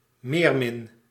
Meermin (Dutch pronunciation: [ˈmeːrmɪn]
Nl-meermin.ogg.mp3